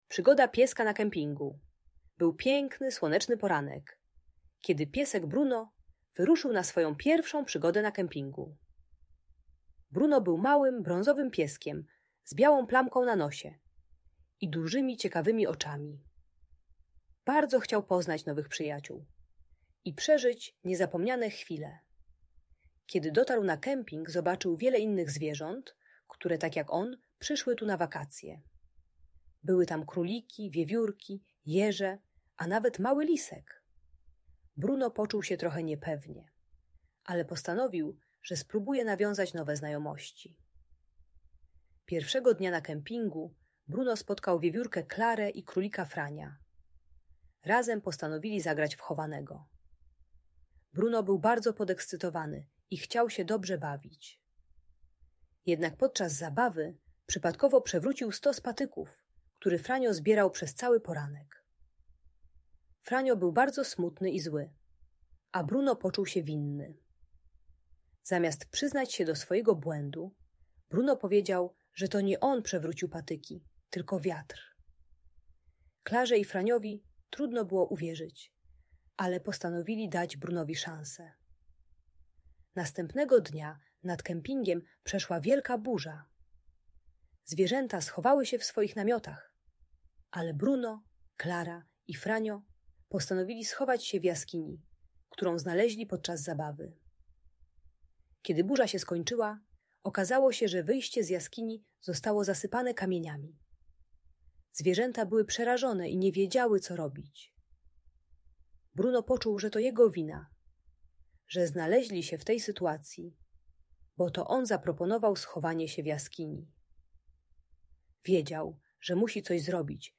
Przygoda Pieska na Kempingu - Niepokojące zachowania | Audiobajka
Historia pieska Bruno uczy, że mówienie prawdy buduje zaufanie i przyjaźń. Audiobajka o kłamstwie i przyznawaniu się do winy pomaga dziecku zrozumieć wartość szczerości.